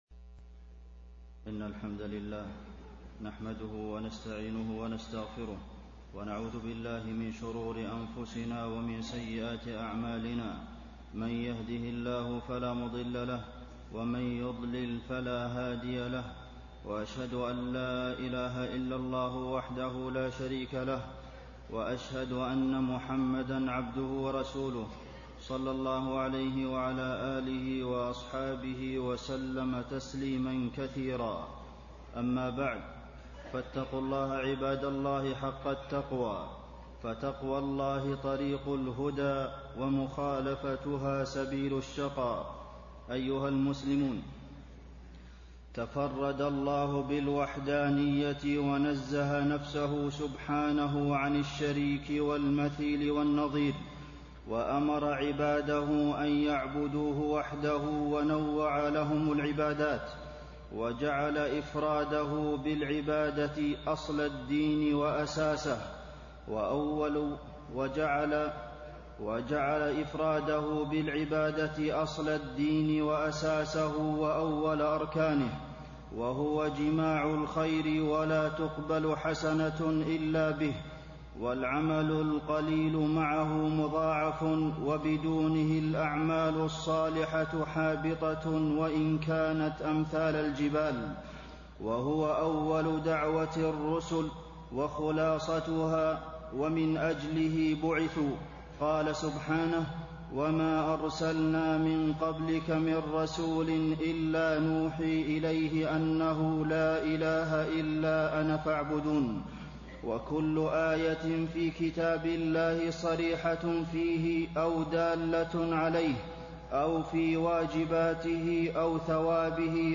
تاريخ النشر ٩ جمادى الآخرة ١٤٣٤ هـ المكان: المسجد النبوي الشيخ: فضيلة الشيخ د. عبدالمحسن بن محمد القاسم فضيلة الشيخ د. عبدالمحسن بن محمد القاسم فضل التوحيد